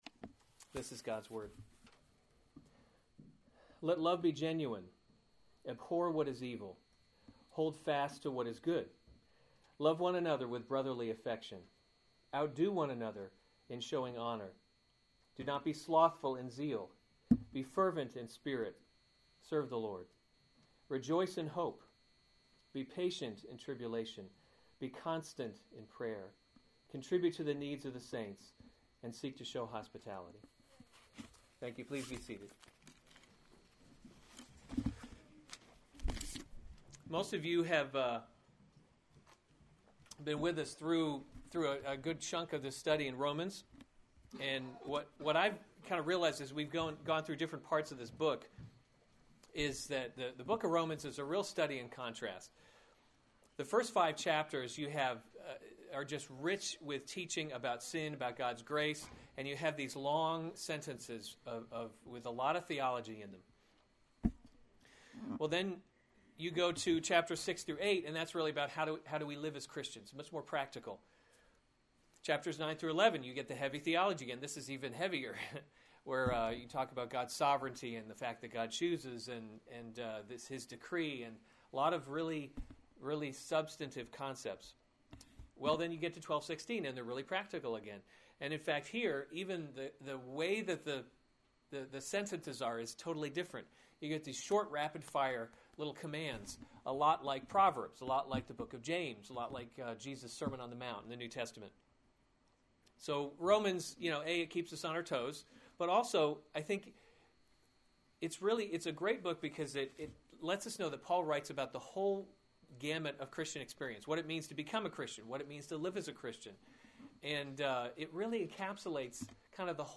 February 28, 2015 Romans – God’s Glory in Salvation series Weekly Sunday Service Save/Download this sermon Romans 12:9-13 Other sermons from Romans Marks of the True Christian 9 Let love be […]